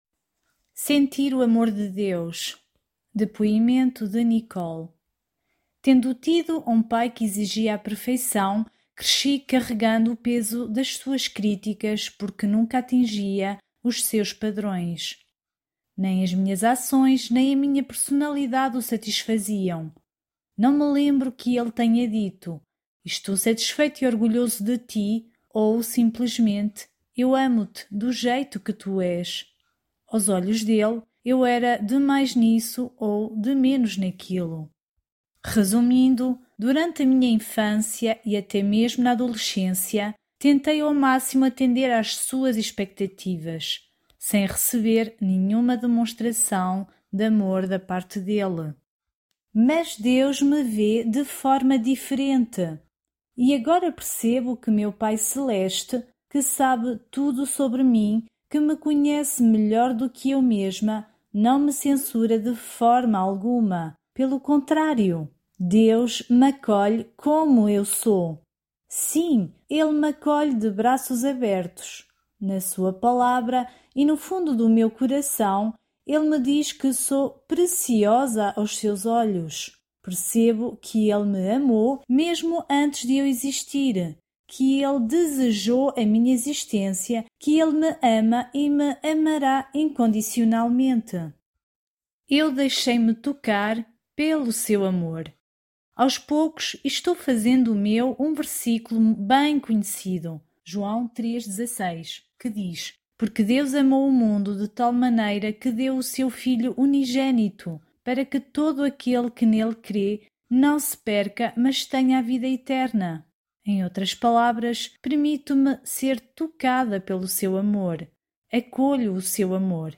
Áudios, Testemunhos